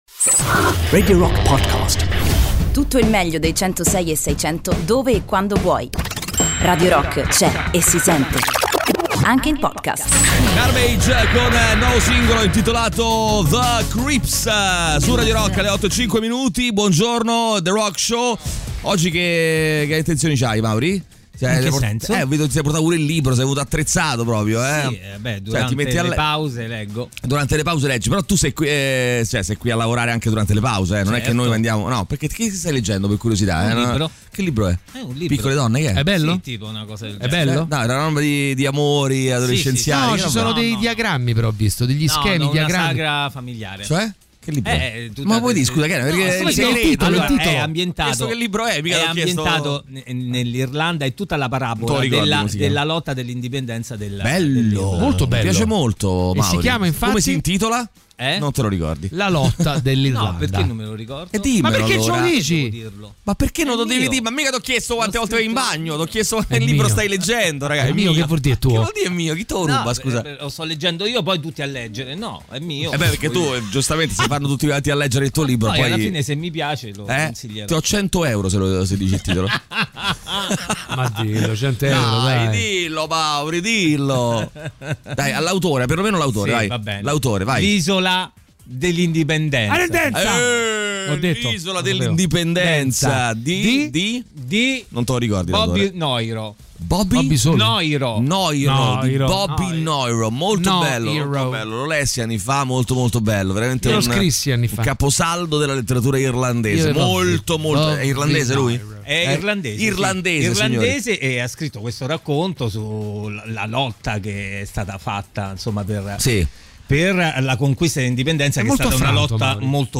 Ospite L'UOMO DELLE STELLE.